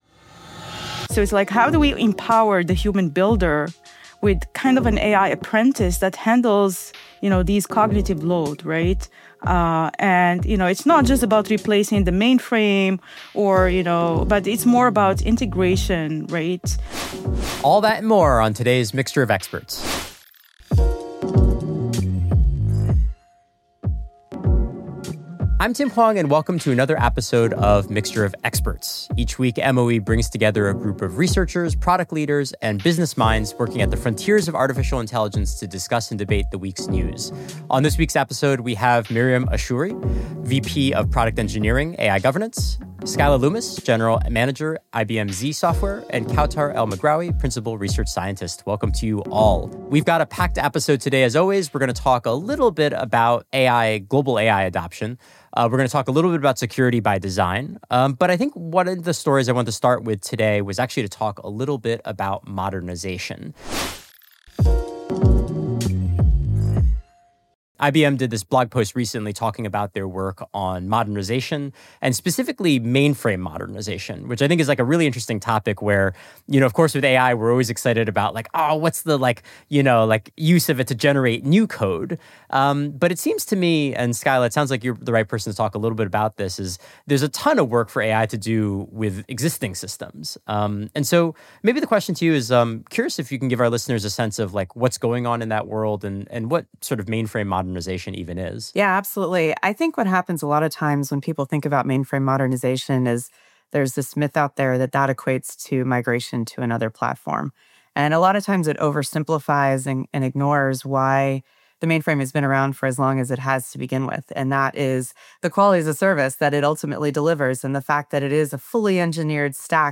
We dive into conversation around AI-powered mainframe modernization and AI builders.